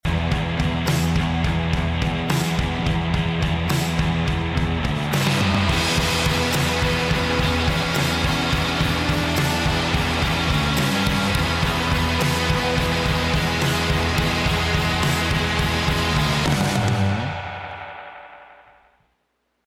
badass psych rock band
got a live session to share!